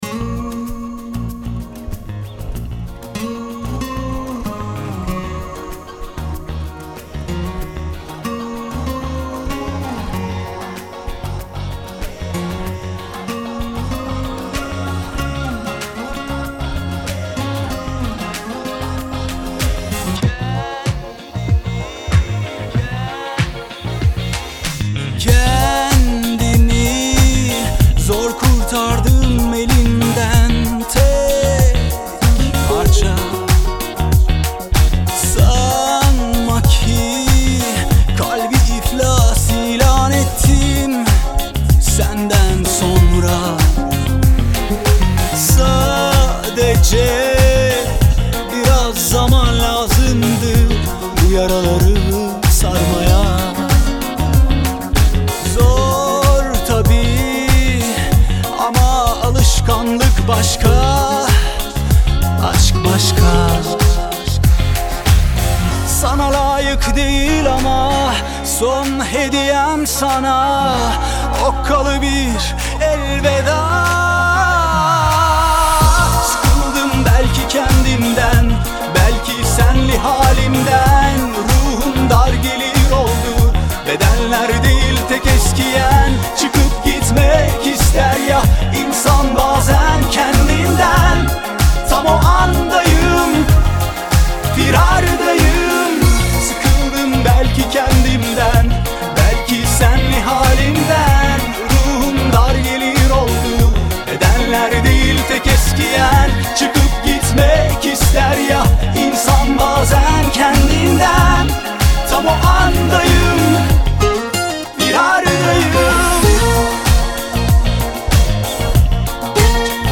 Трек размещён в разделе Турецкая музыка / Поп / 2022.